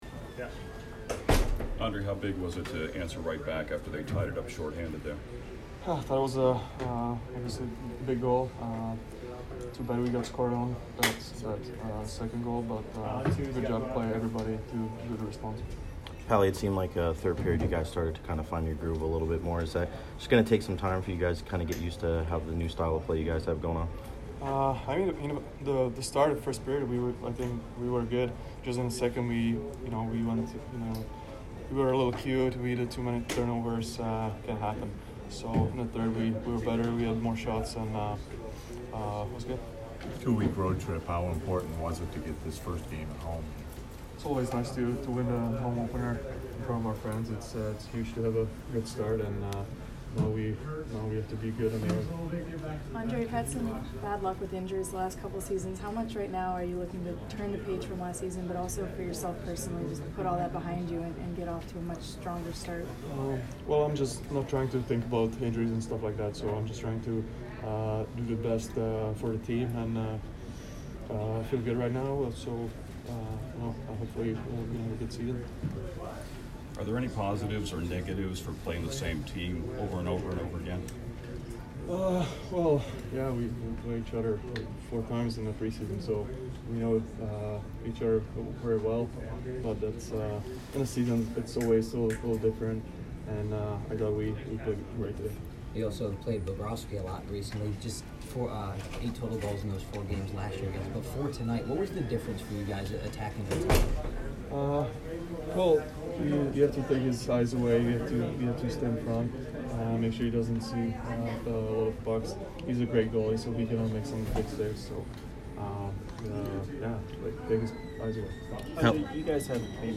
Ondrej Palat post-game 10/3